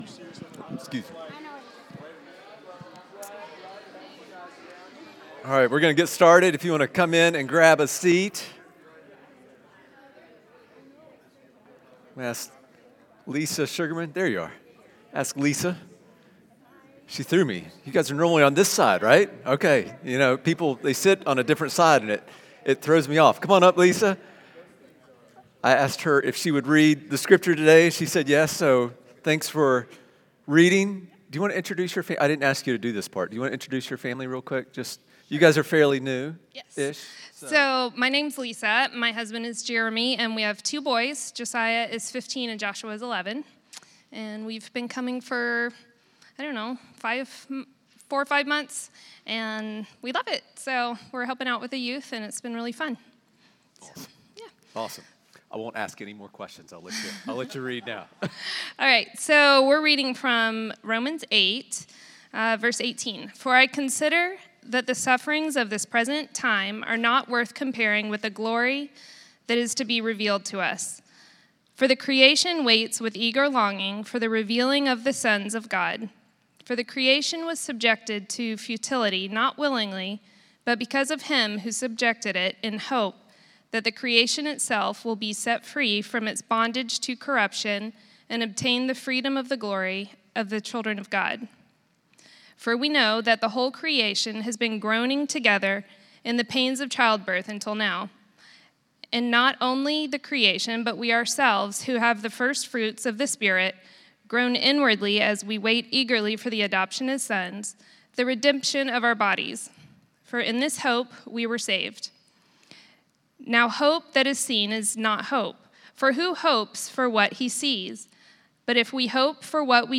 Sermons | Radiant Church